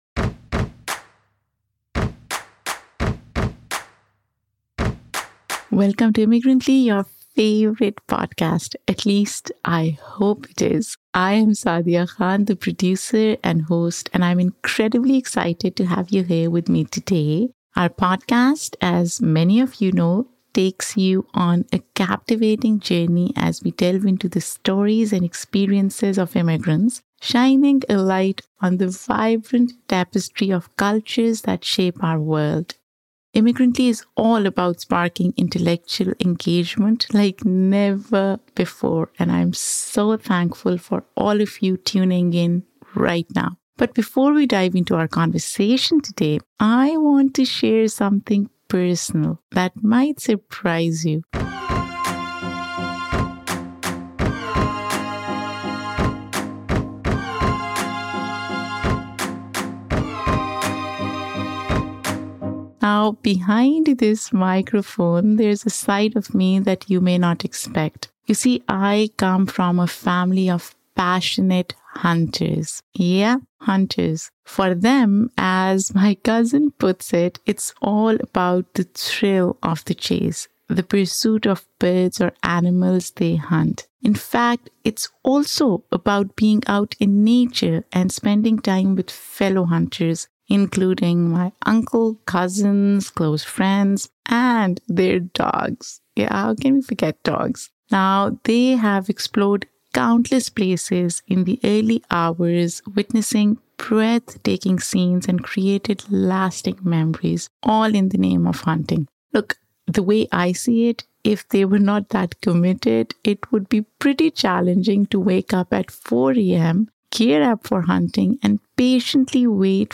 This is indeed an enlightening and entertaining conversation with this extraordinary multi-talented individual.